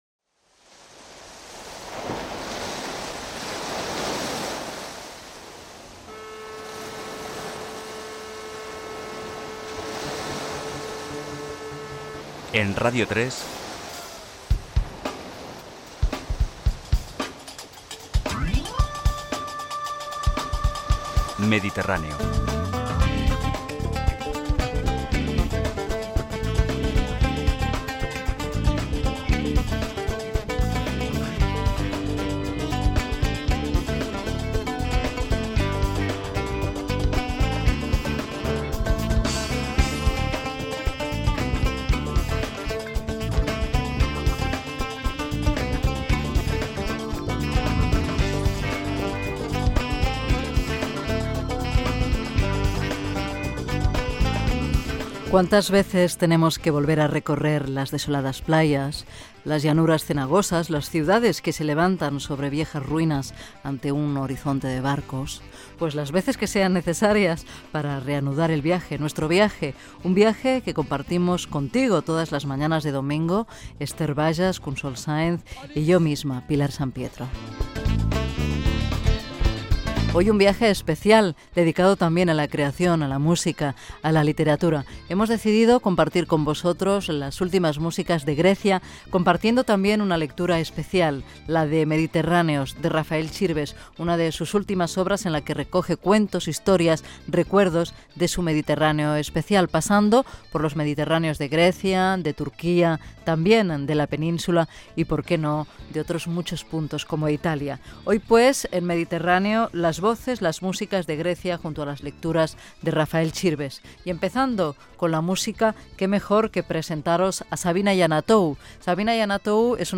Careta del programa, equip, sumari, tema musical, declaracions de la cantant Savina Yannatou que va actuar al Festival Barnasants, indicatiu, fragment del llibre "Mediterráneos" de Rafael Chirbes